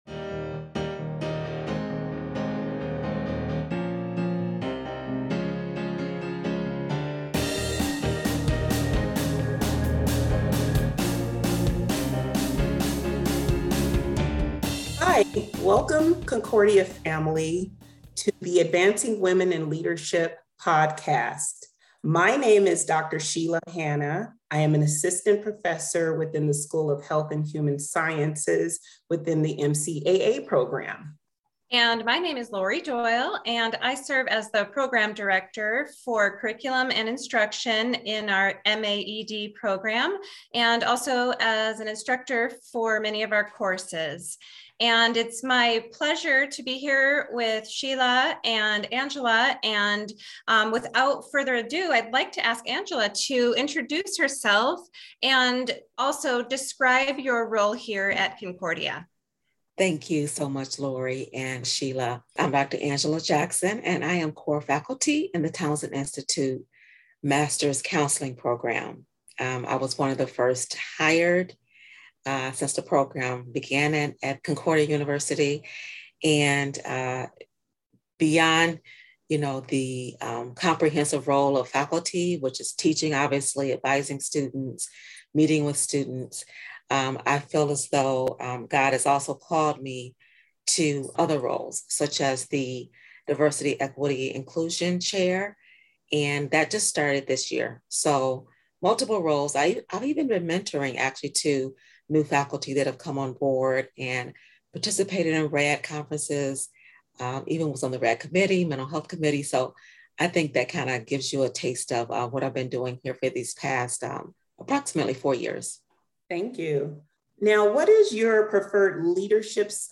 AWiL Interview